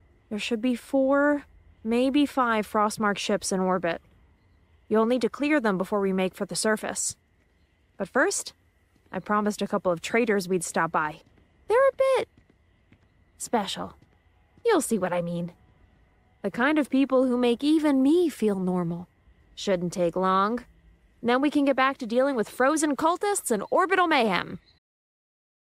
hell, fein, zart, sehr variabel
Jung (18-30)
Audio Drama (Hörspiel), Audiobook (Hörbuch), Game, Scene